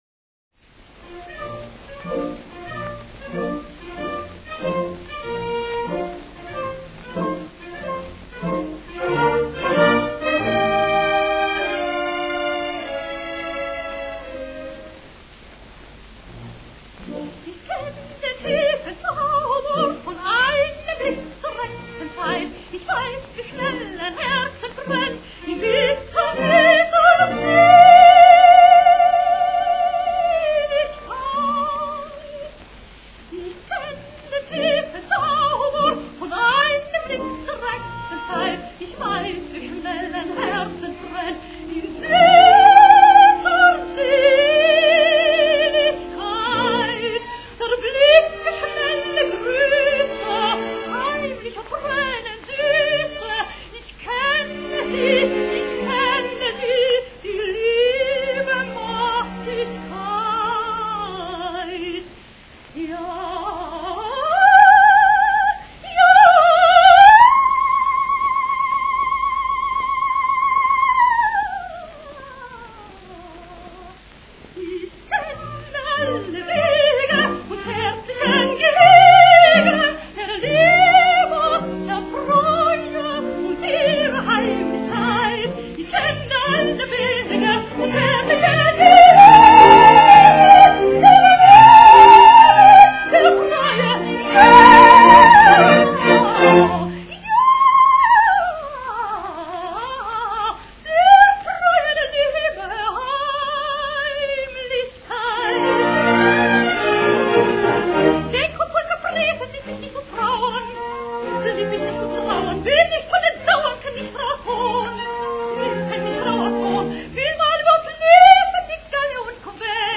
Austrian-American soprano, 1895 - 1974
Hers is a beautiful, delicate and silvery voice of enchanting sweetness. She is a superb artist, singing not only with a remarkable agility, but also with an entirely imaginative style.